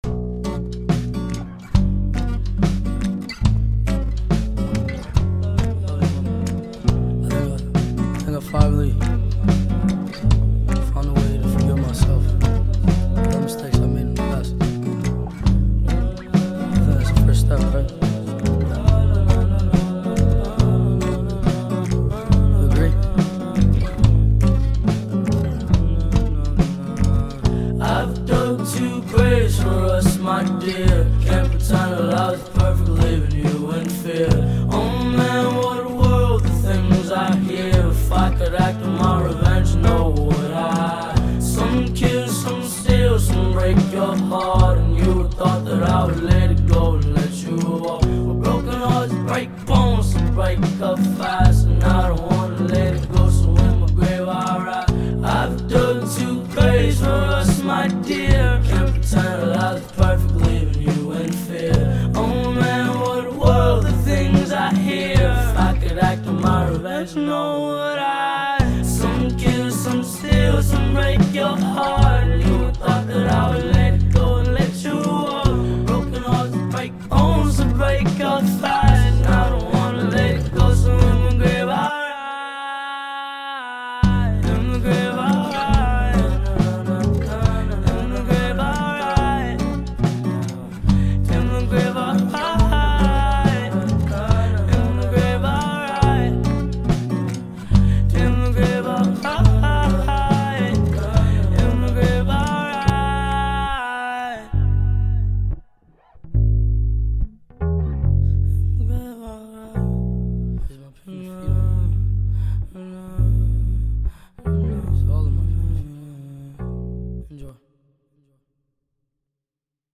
2025-02-14 20:57:05 Gênero: Acoustic Views